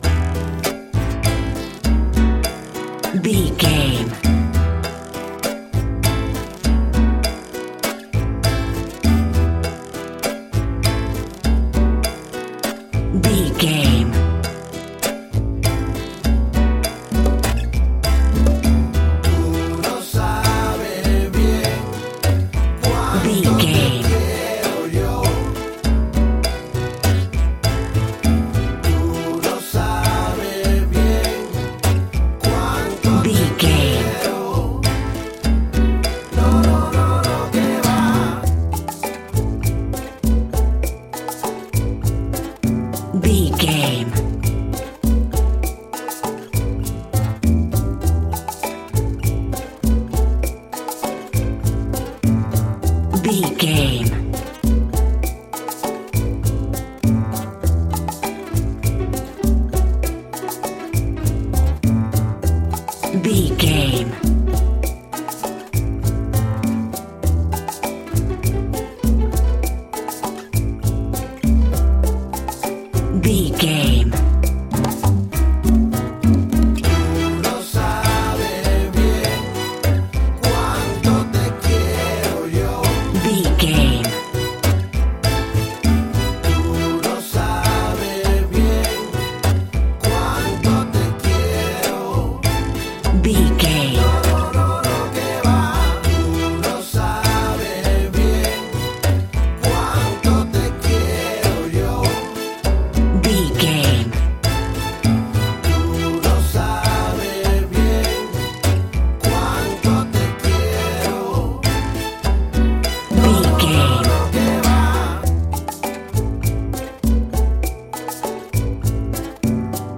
An exotic and colorful piece of Espanic and Latin music.
Aeolian/Minor
salsa
maracas
percussion spanish guitar
latin guitar